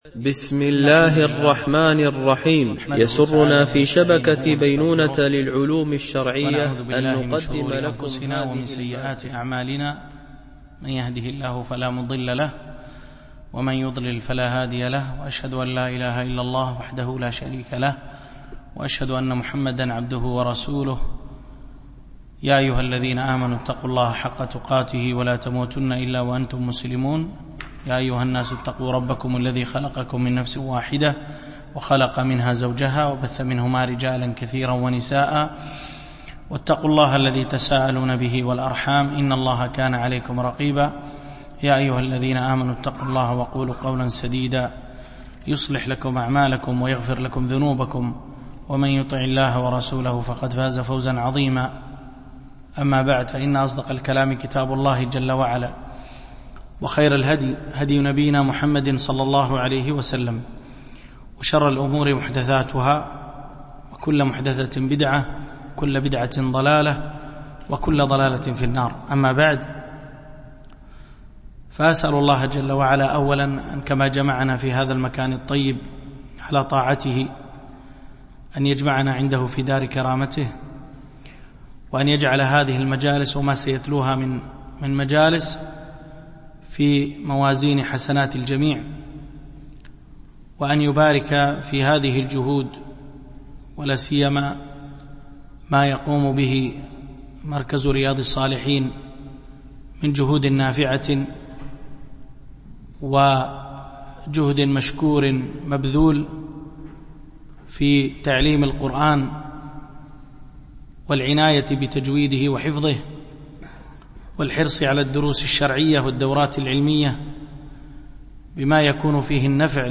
الدرس 1